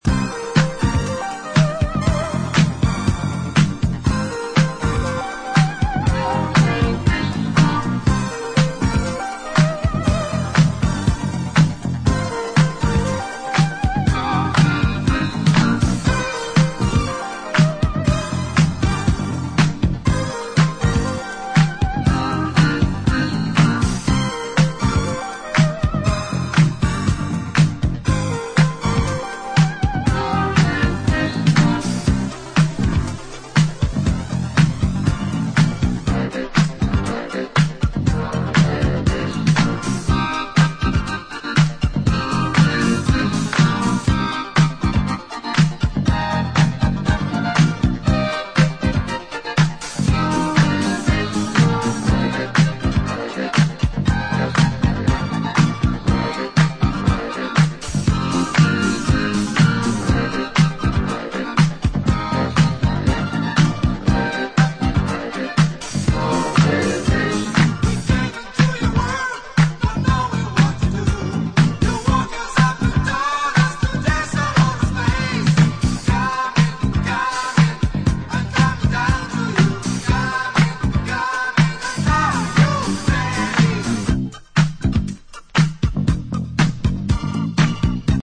all in the disco edit and dub versions.